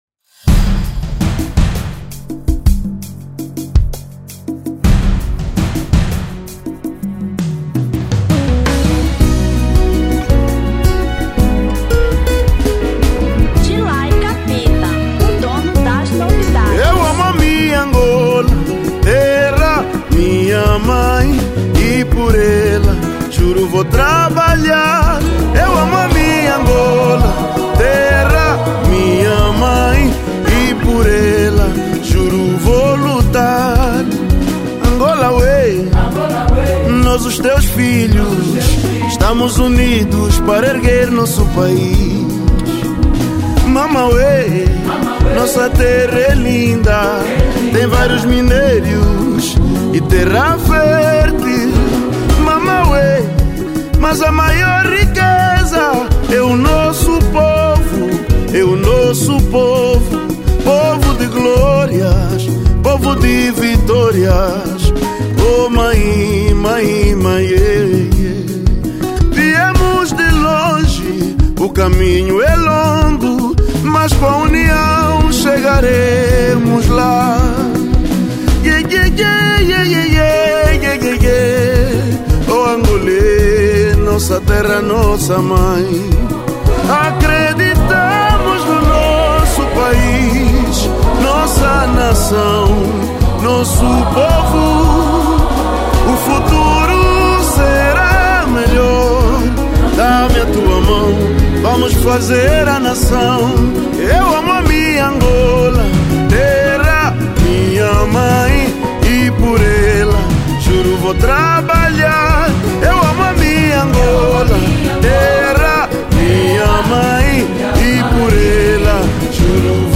Kizomba 2020